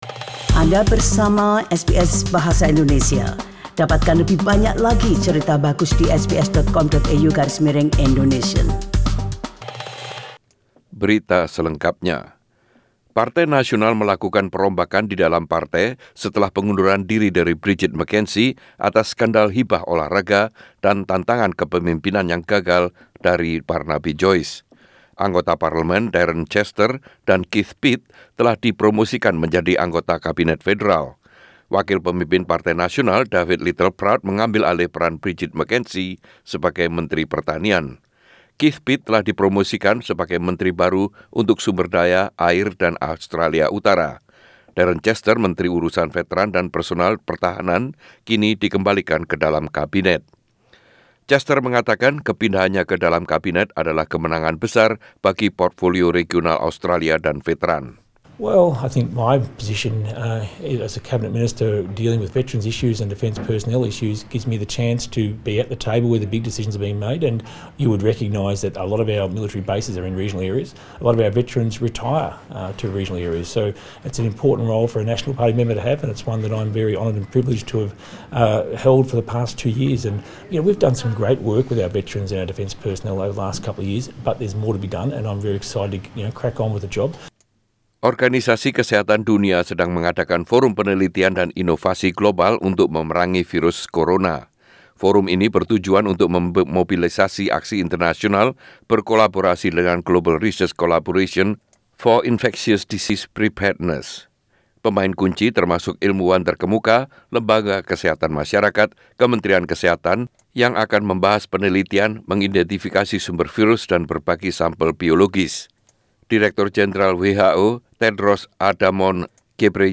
SBS Radio News in Indonesian - 07/02/2020